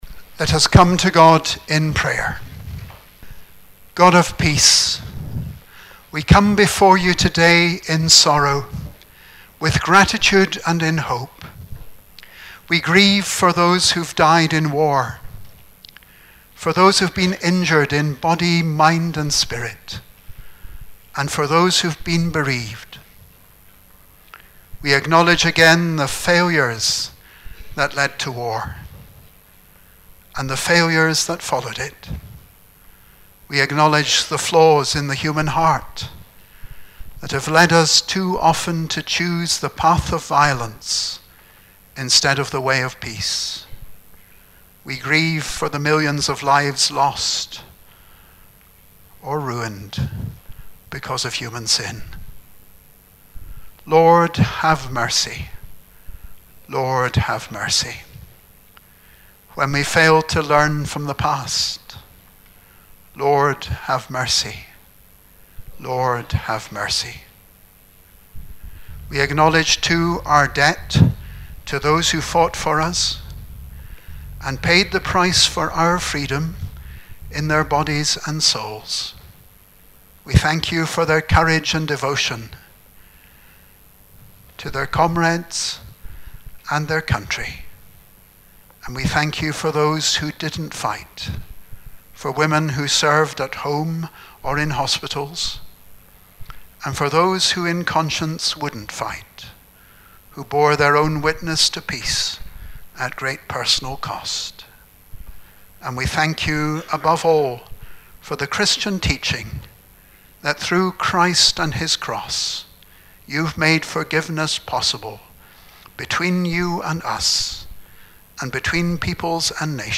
Remembrance Sunday - 10 November 2019
prayerconfession.mp3